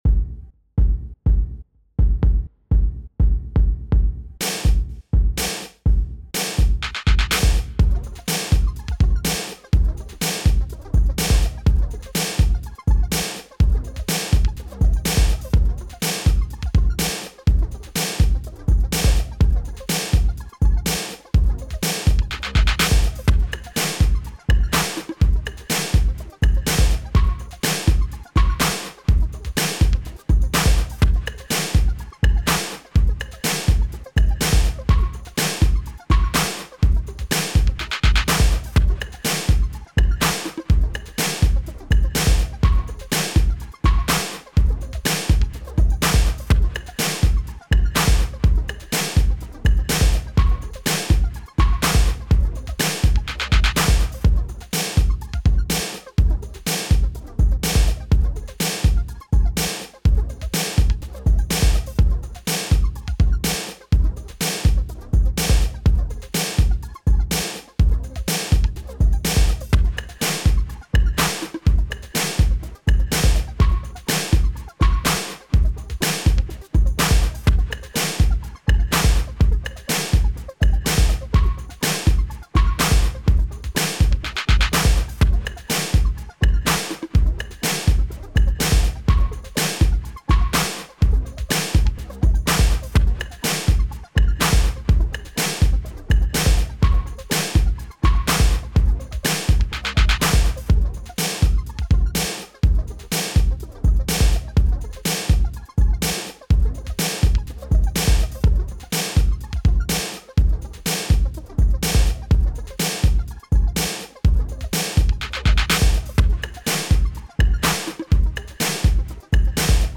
80s, Dance
Bb Major